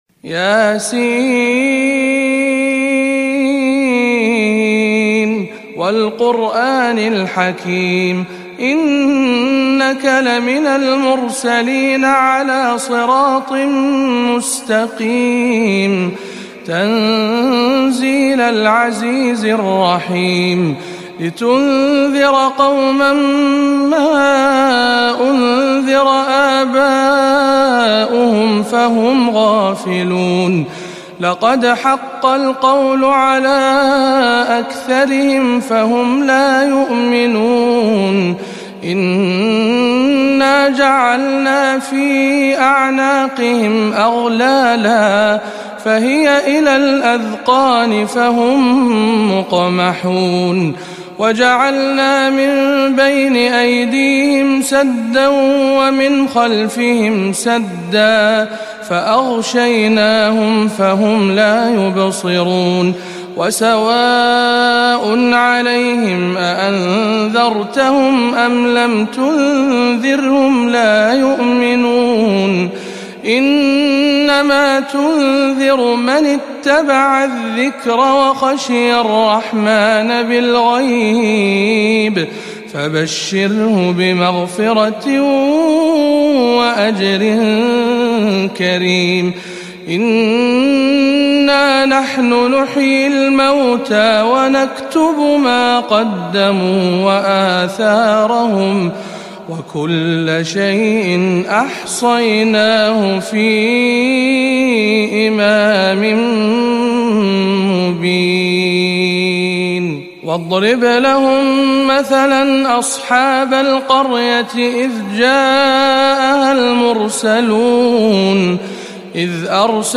05. سورة يس بمسجد الزبن بالسرة بدولة الكويت - رمضان 1437 هـ